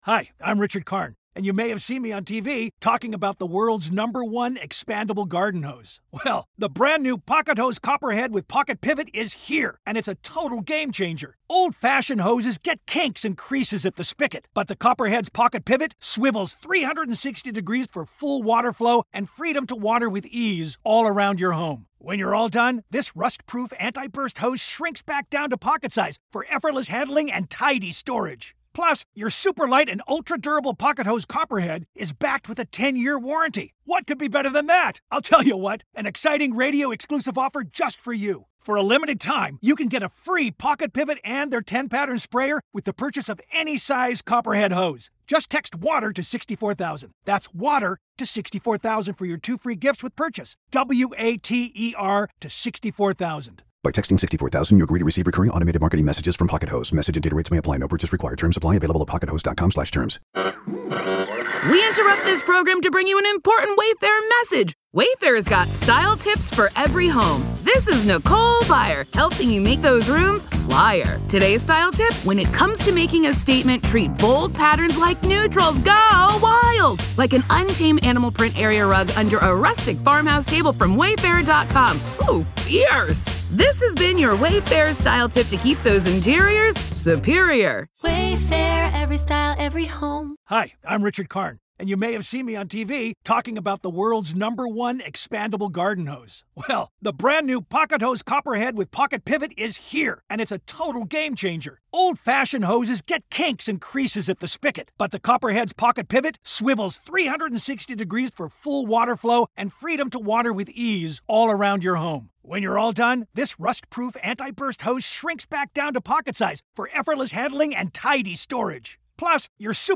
satire